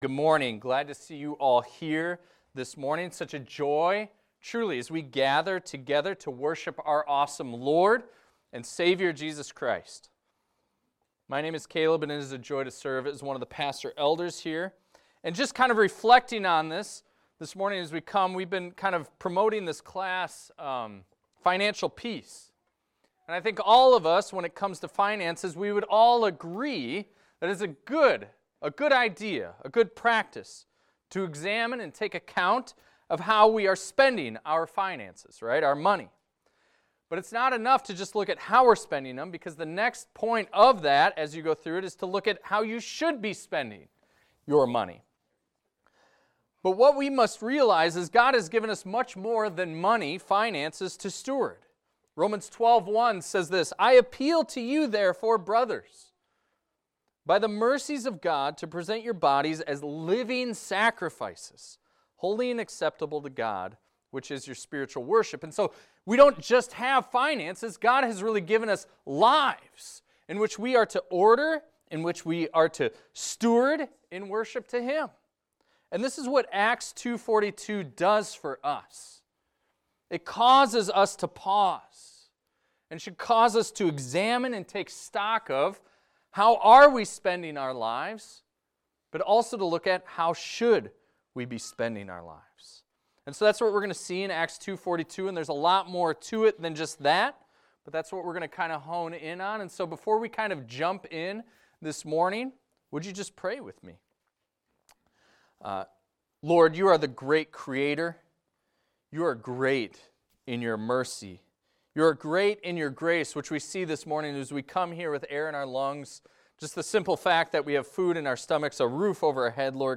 This is a recording of a sermon titled, "Four Devotions."